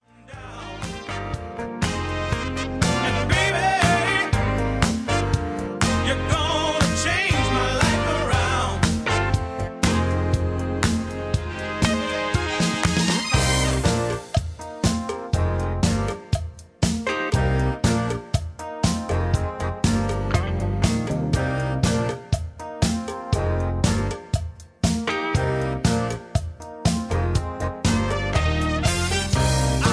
Key-Cm) Karaoke MP3 Backing Tracks
Just Plain & Simply "GREAT MUSIC" (No Lyrics).